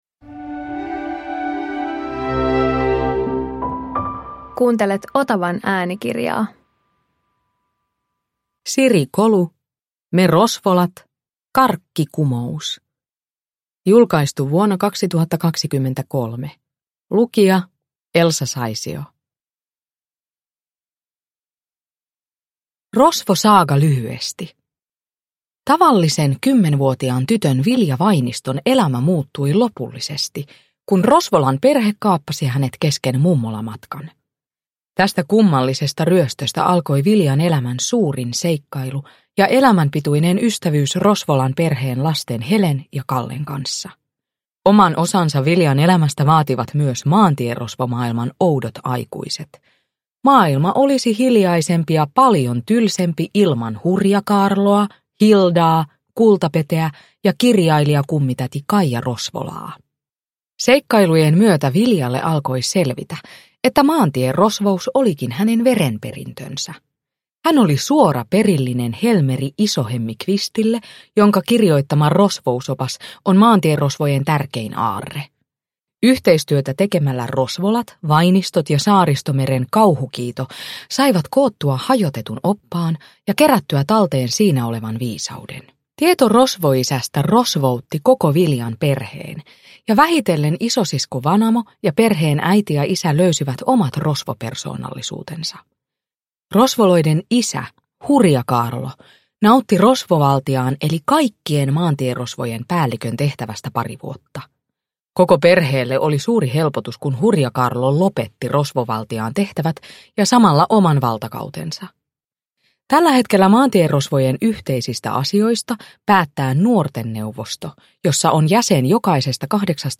Me Rosvolat - Karkkikumous! – Ljudbok – Laddas ner
Uppläsare: Elsa Saisio